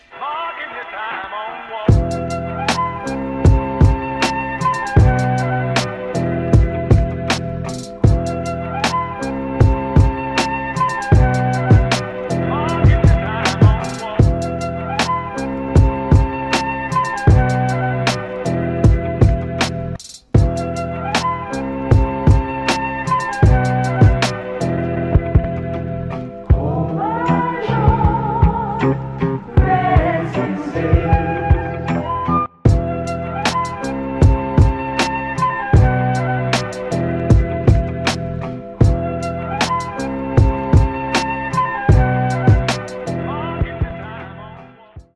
• Качество: 320, Stereo
Electronic
EDM
спокойные
дуэт
качающие
Флейта
Стиль: trap